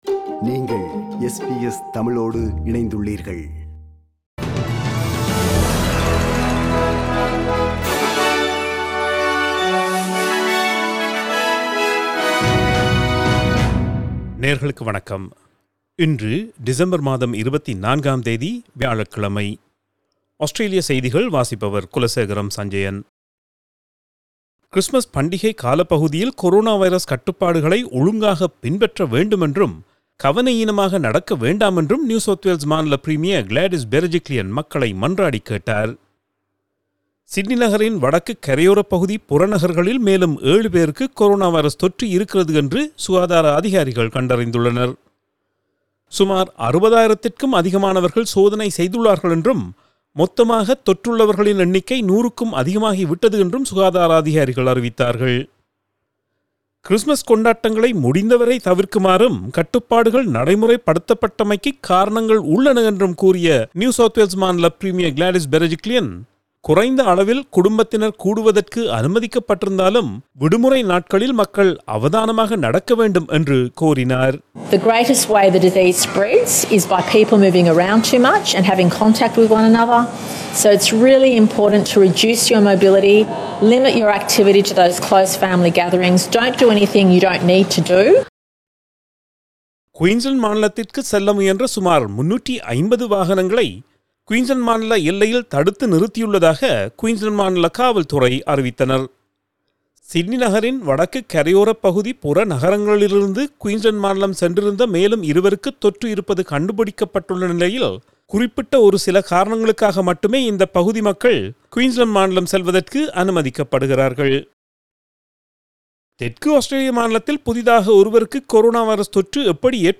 Australian news bulletin for Thursday 24 December 2020.